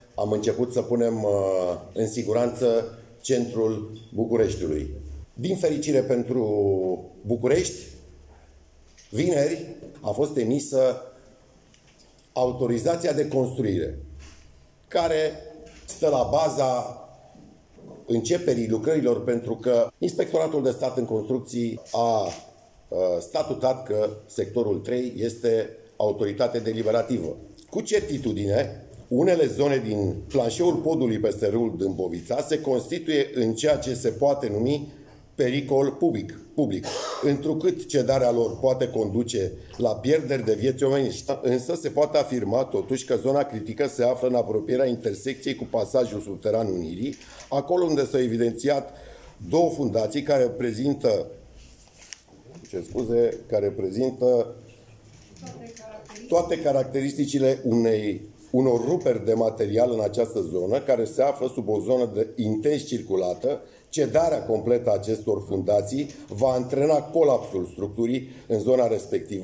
Hotărârea CMBSU și Legea 50/1991 stau și ele la baza intervenției, a mai explicat Daniel Băluță.